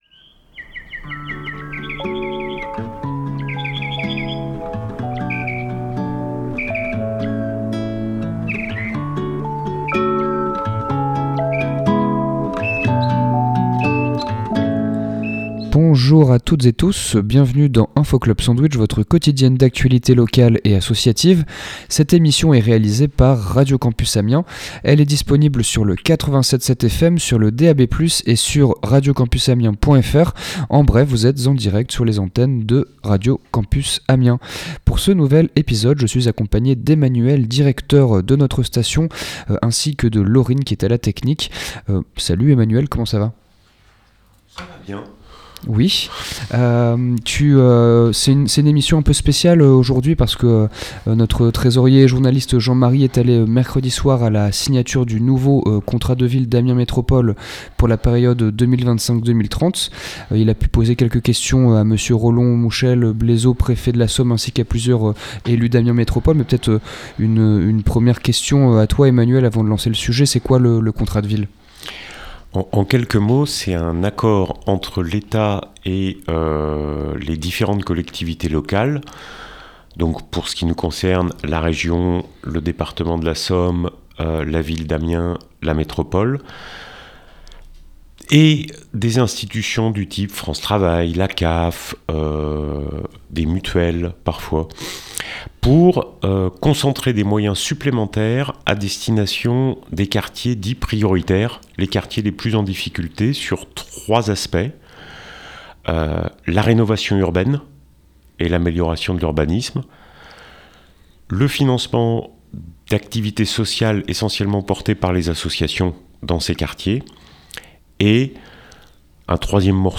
Il a pu poser quelques questions à Monsieur Rollon Mouchel-Blaisot, préfet de la Somme ainsi qu’à plusieurs élu.e.s d’Amiens Métropole.